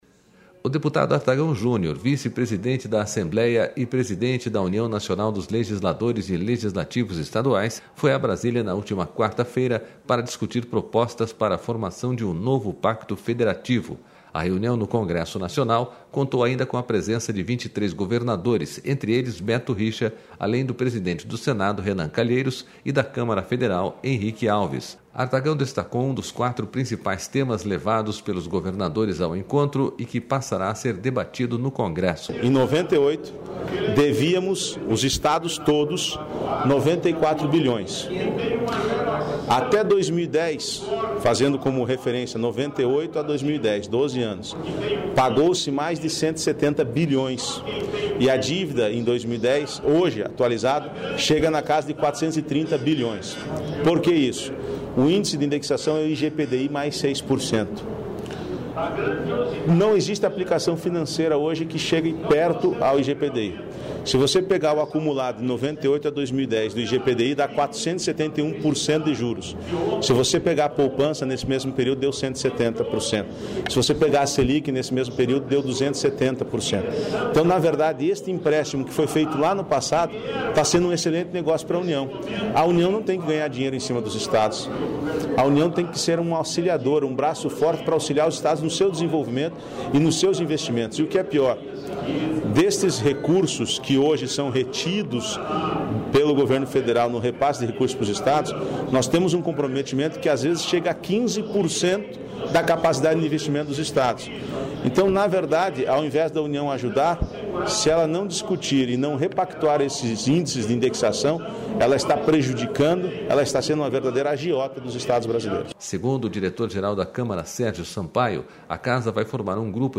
SONORA ARTAGÃO